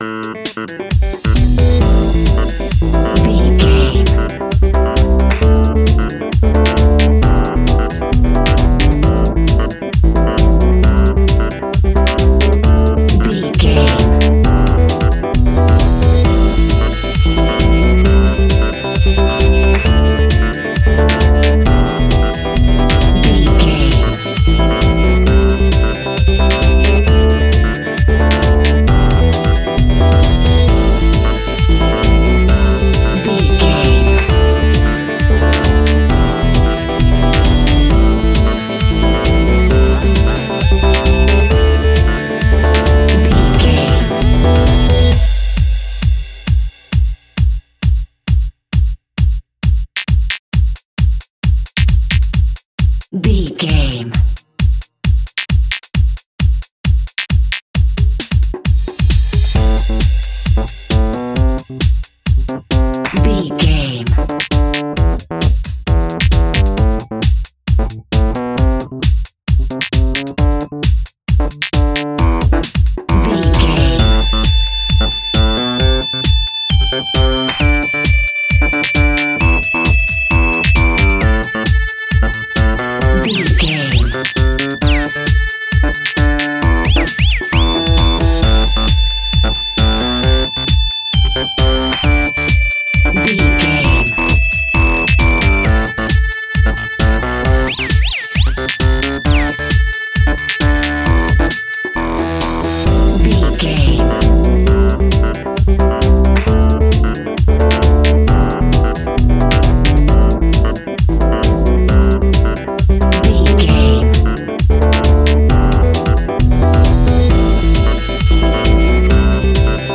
Aeolian/Minor
groovy
uplifting
energetic
bass guitar
electric guitar
drums
synthesiser
electric piano
funky house instrumentals
synth bass
percussion